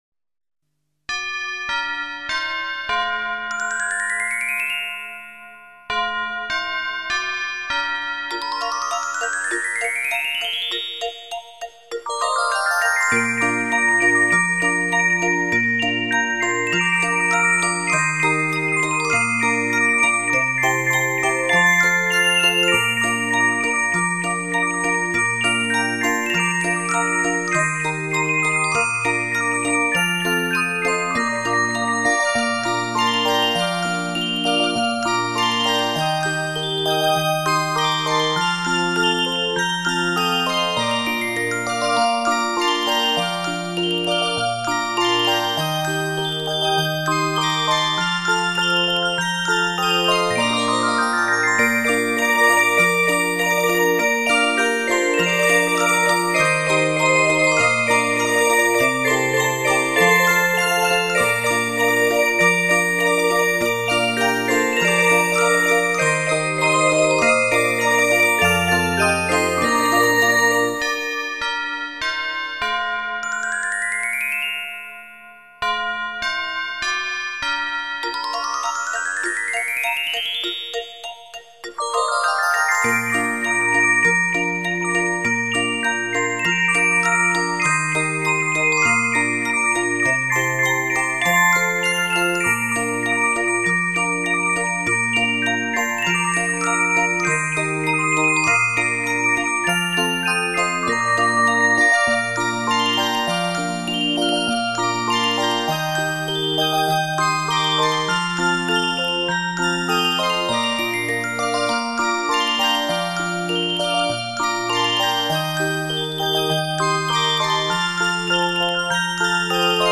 旋律优美，精致无比的乐曲改善心灵状态
静心感恩及深度的心灵修行